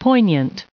added pronounciation and merriam webster audio
2026_poignant.ogg